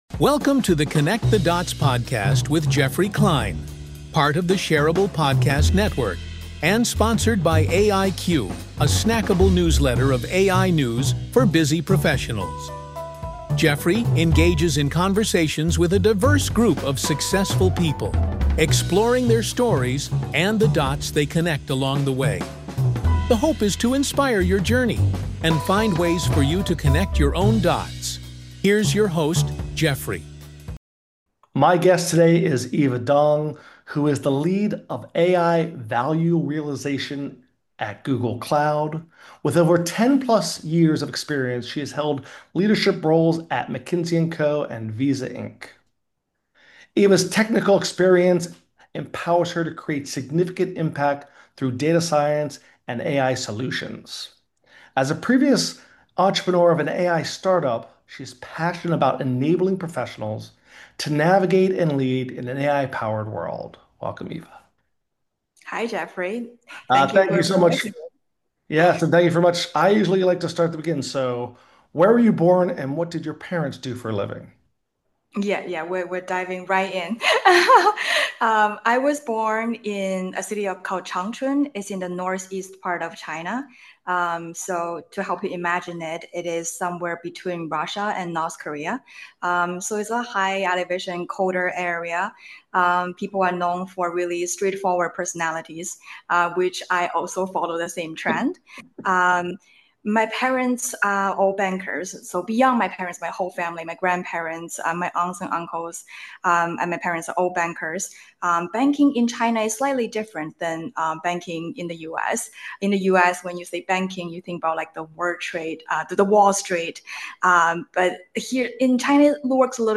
Be a guest on this podcast Language: en Genres: Business , Marketing Contact email: Get it Feed URL: Get it iTunes ID: Get it Get all podcast data Listen Now...